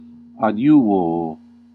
Ääntäminen
IPA : /hɛlp/